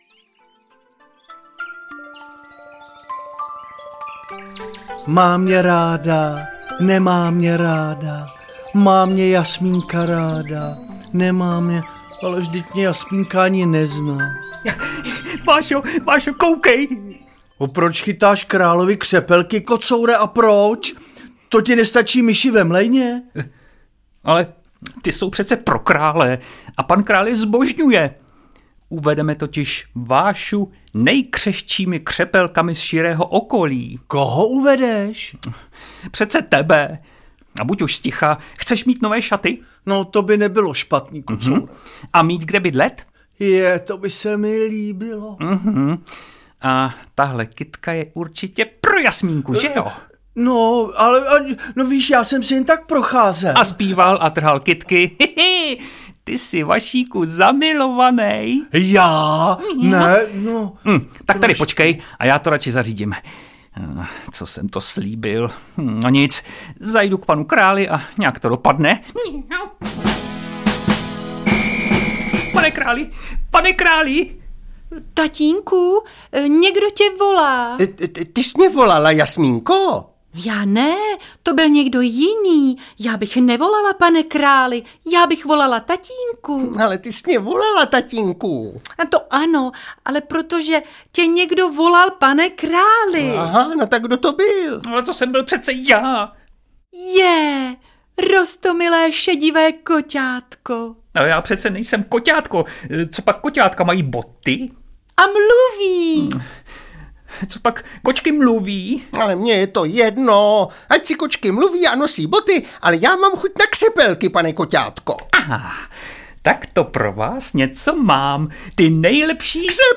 Audiokniha KORA PAÁ do auta i do postýlky, kteoru napsaly a čtou Buchty a Loutky. Divadelní pohádky Kocour v botách a Vánoční raketa.
Ukázka z knihy
Pohádková audiokniha "KORA PÁÁ" je audio úprava dětských představení Buchet a loutek.